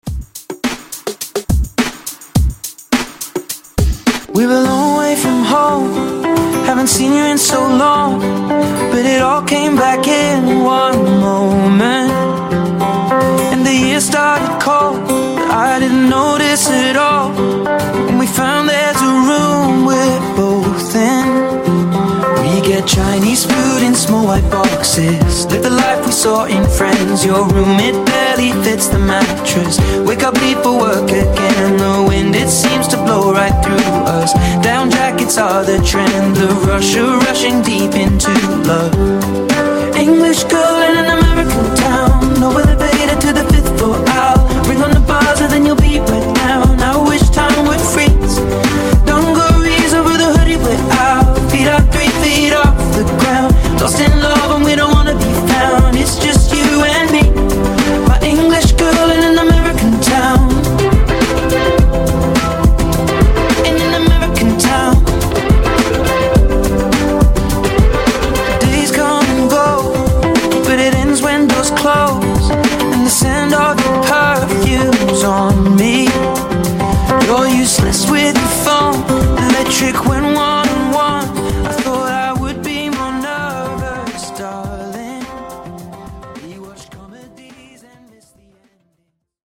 Genre: DANCE Version: Clean BPM: 110 Time